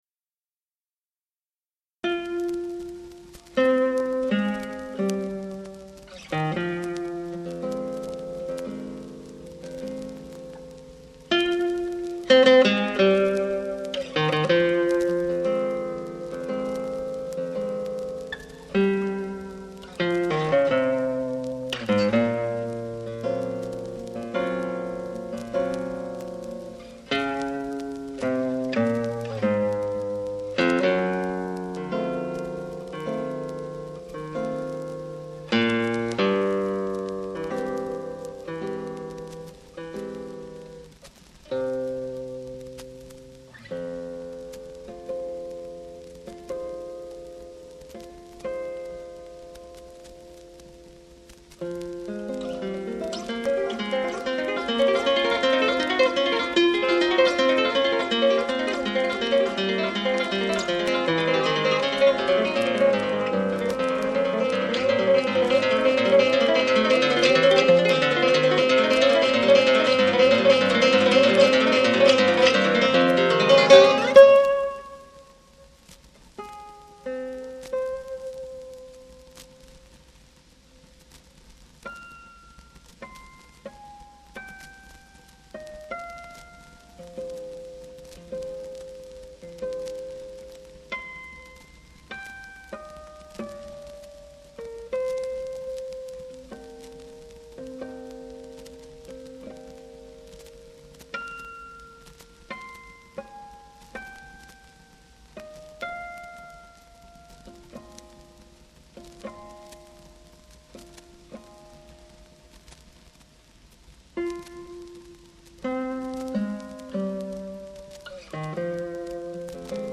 IN E MAJOR